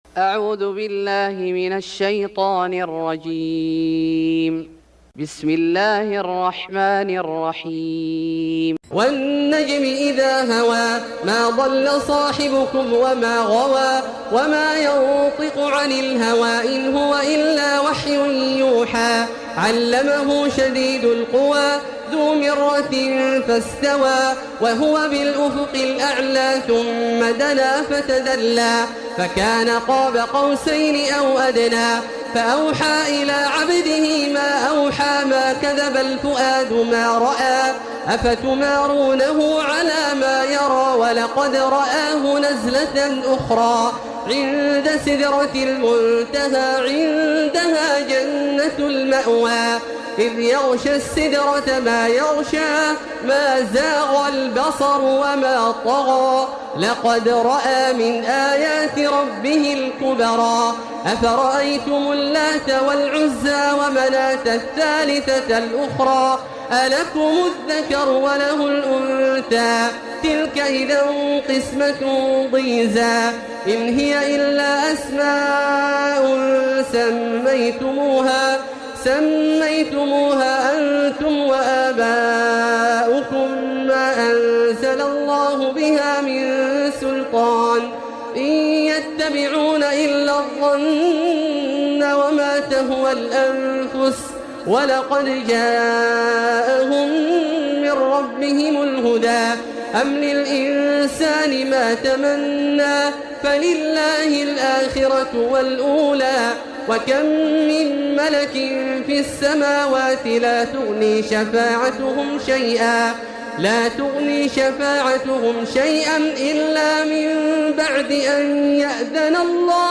سورة النجم Surat An-Najm > مصحف الشيخ عبدالله الجهني من الحرم المكي > المصحف - تلاوات الحرمين